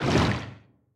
Sfx_creature_bruteshark_swim_fast_05.ogg